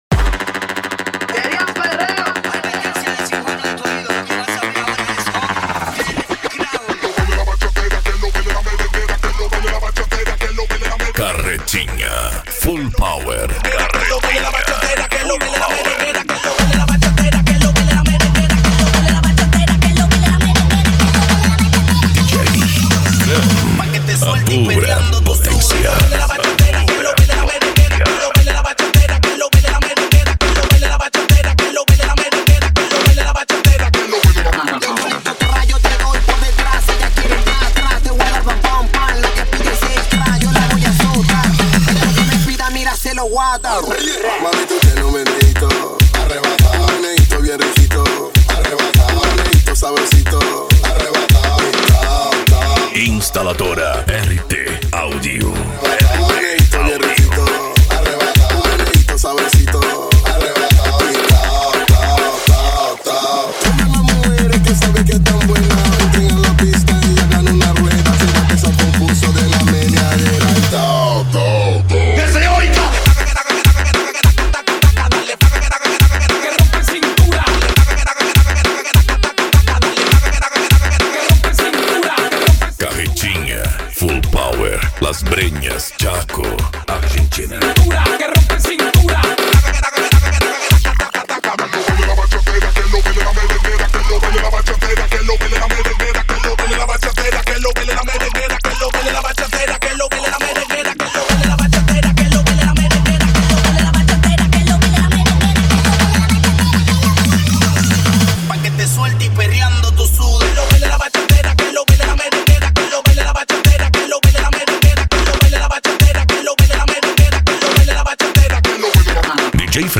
PANCADÃO
Remix